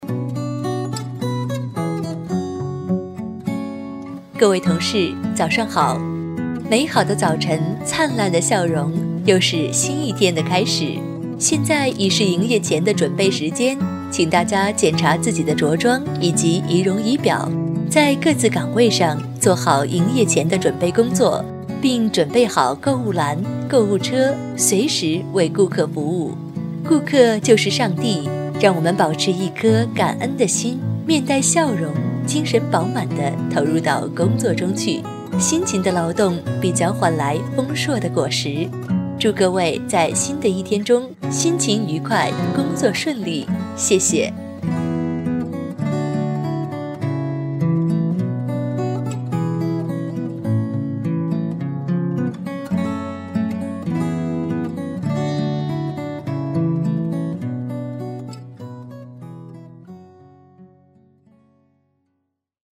女声配音
提示音女国73A